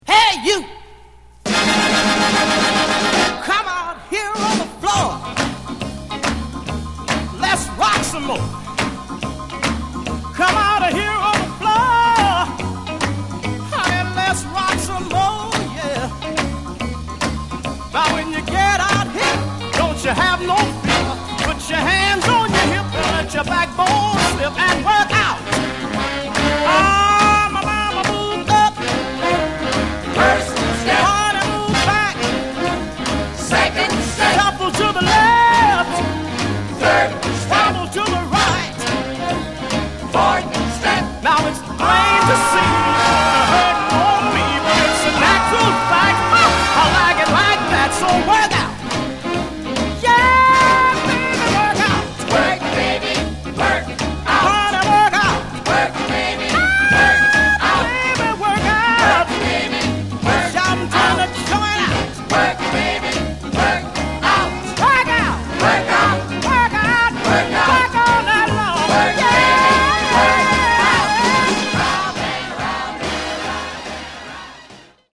Genre: Other Northern Soul
the music so joyous